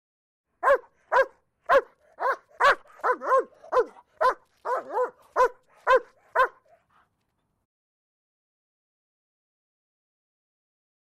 Лай собаки в отдалении